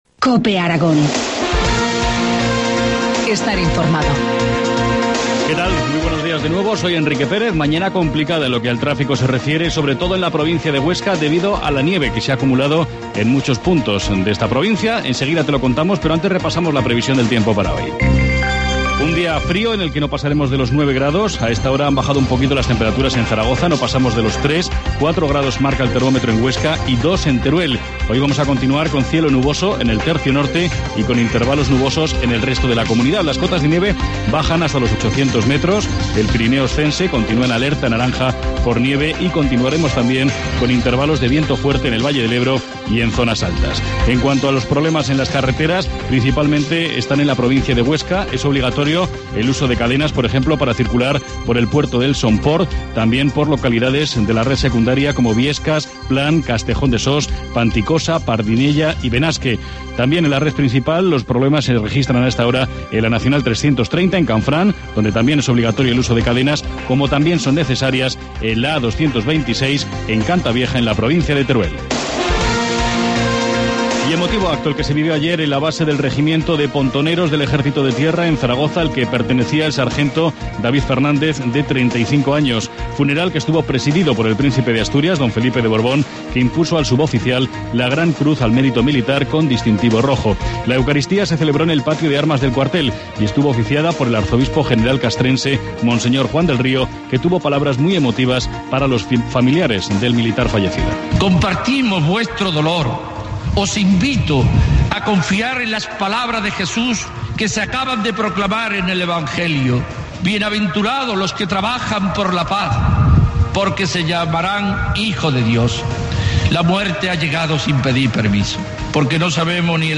Informativo matinal, lunes 14 de enero, 8.25 horas